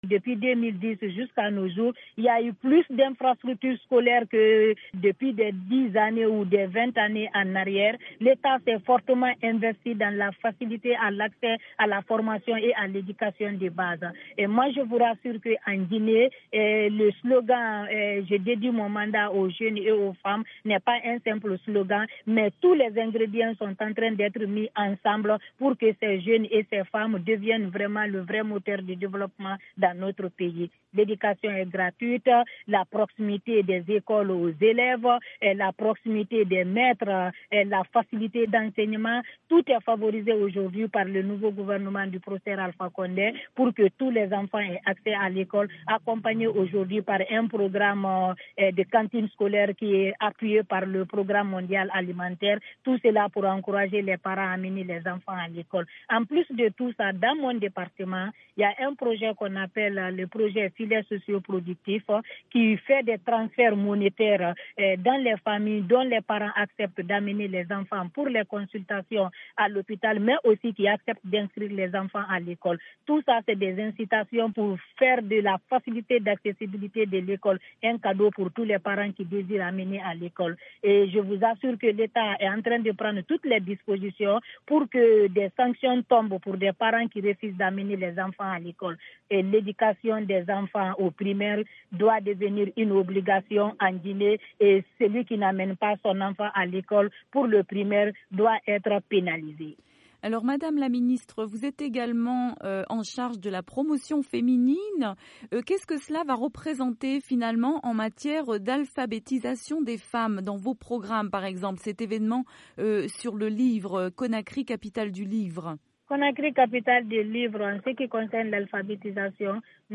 Conakry Capitale du Livre 2017: «L’éducation des enfants au primaire doit devenir une obligation en Guinée, et celui qui n’emmène pas son enfant à l’école doit être pénalisé», a déclaré à VOA Afrique Sanaba Kaba, Ministre de l’Action Sociale, de la Promotion Féminine et de l’Enfance.